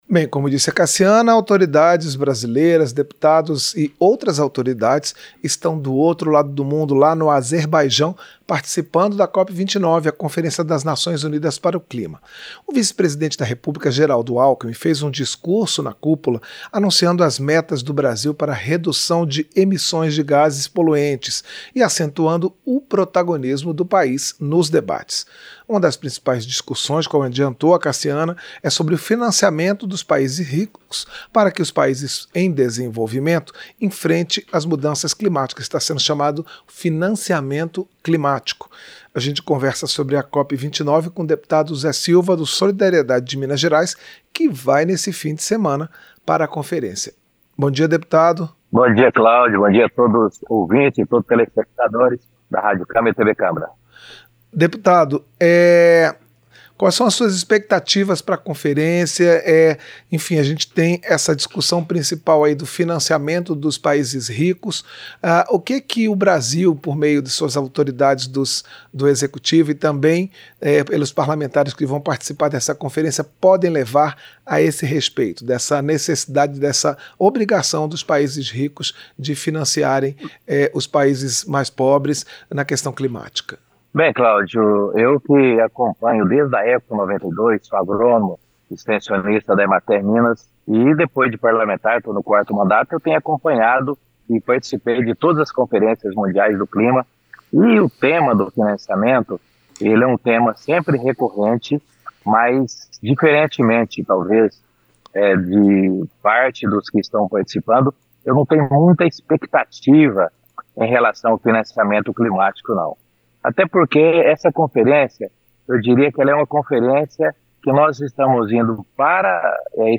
Entrevista - Dep. Zé Silva (Solidariedade-MG)